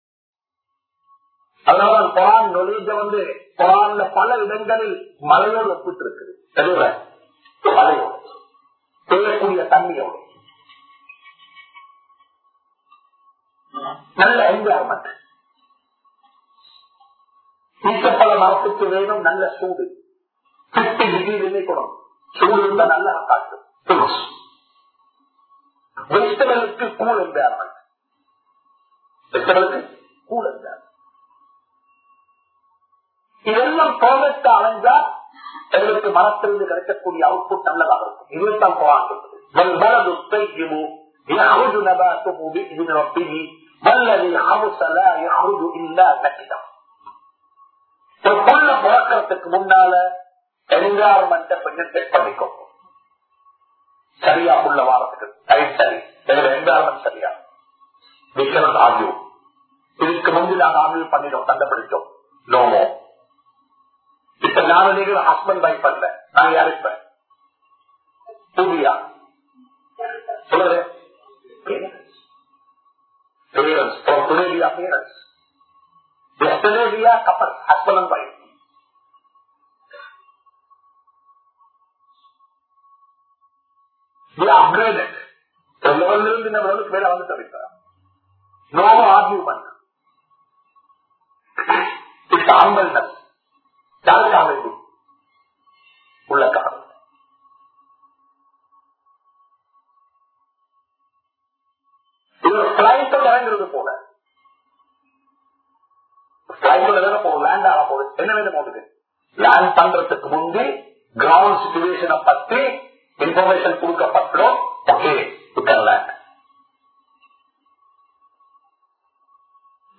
Munmathiriyana Petrorkal-Day02(முன்மாதிரியான பெற்றோர்கள்-Day02) | Audio Bayans | All Ceylon Muslim Youth Community | Addalaichenai
MICH Hall Lily Avenue